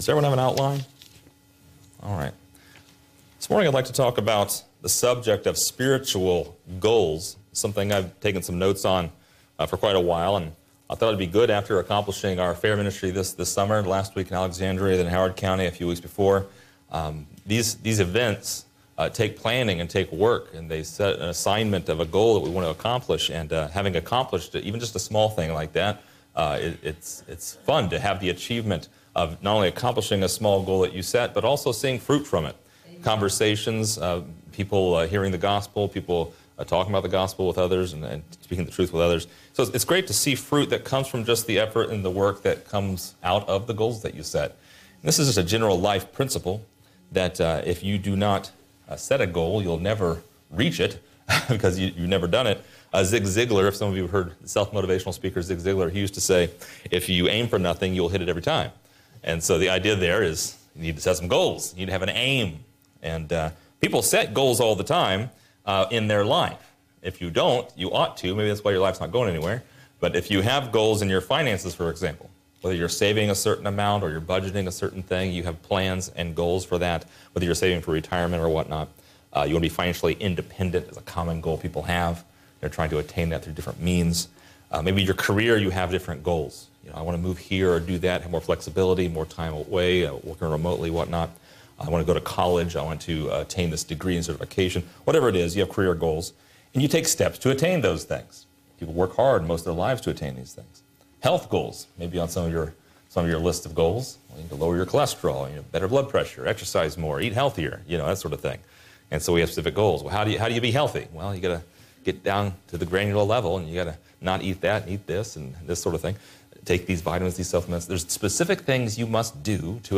So what are these important objectives, exactly? Find out in this lesson!